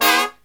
FALL HIT04-L.wav